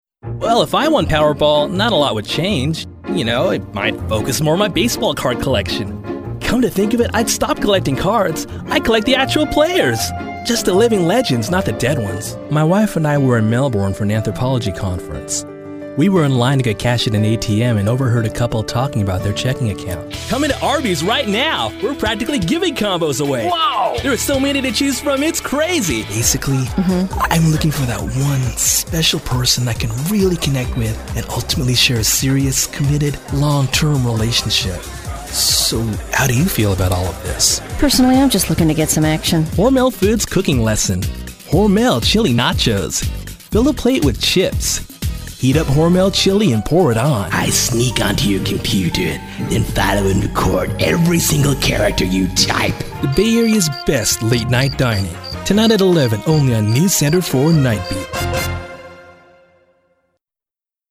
Young Male Talent: I do commercials and narration.
Sprechprobe: Sonstiges (Muttersprache):